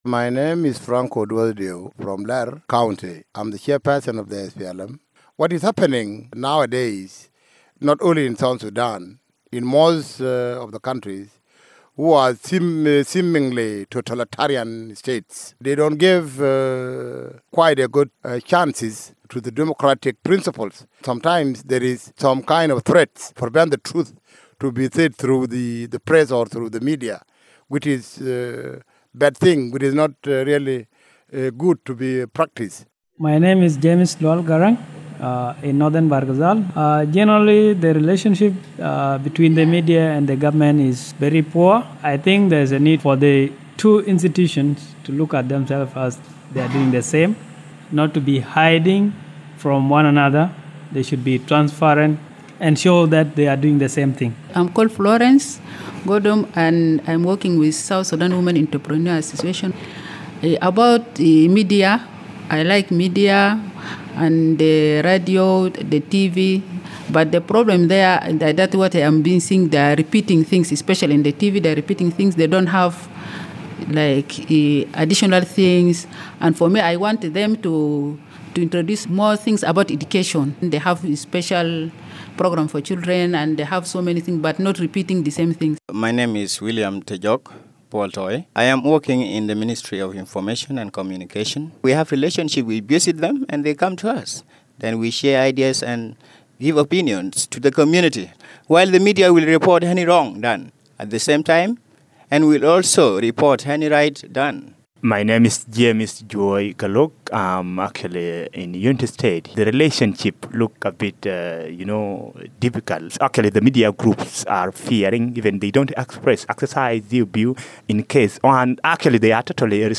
Citizen Vox Pops Media Freedom In South Sudan